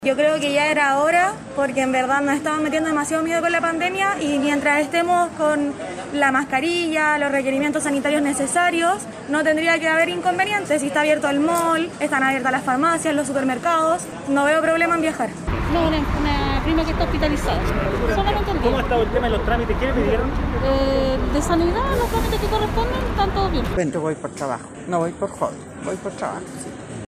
Por su parte, los pasajeros valoraron la apertura de los terminales de Viña del Mar y Valparaíso.